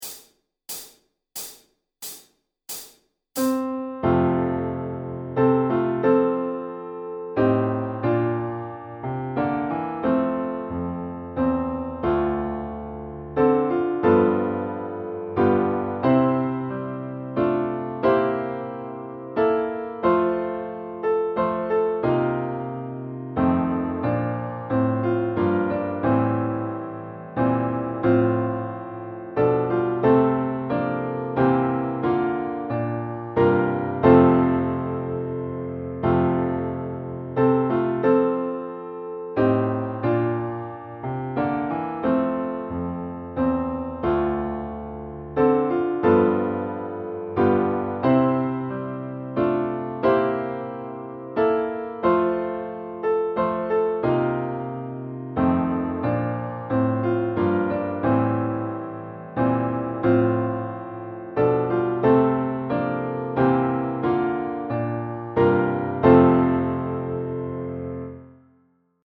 Traditional für Klavier und Trompete.